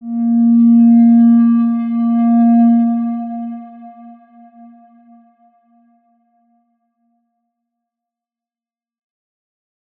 X_Windwistle-A#2-ff.wav